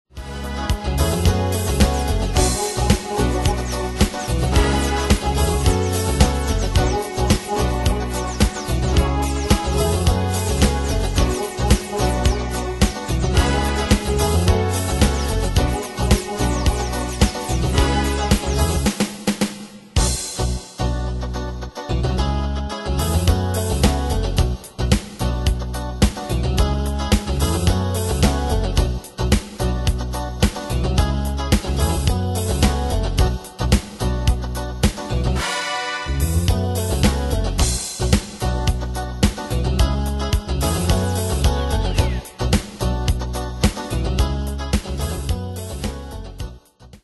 Style: Dance Ane/Year: 1997 Tempo: 109 Durée/Time: 4.14
Pro Backing Tracks